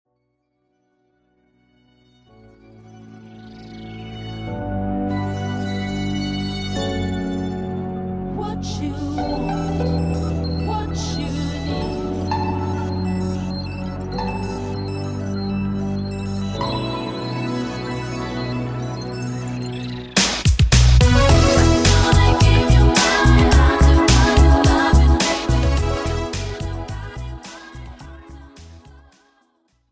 Pop , R&B